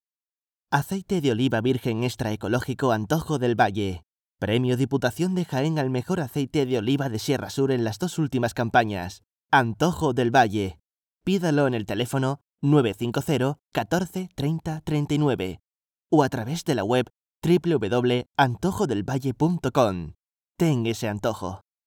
Spanish speaker, voice over, young voice, voice actor, station voice.
kastilisch
Sprechprobe: Werbung (Muttersprache):